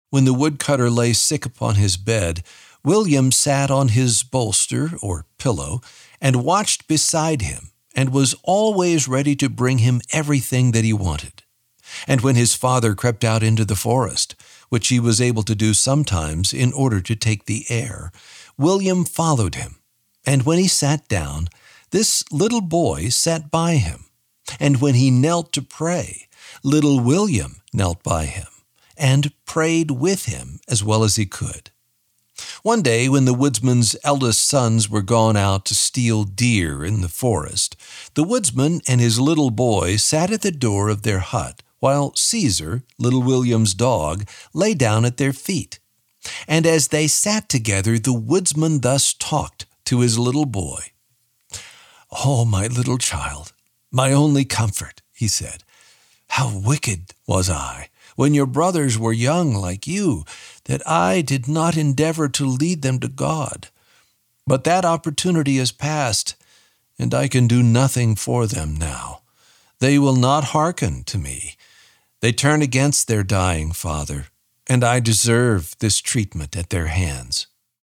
This is an audiobook, not a Lamplighter Theatre drama.
The-Little-Woodsman-Audiobook-Sample.mp3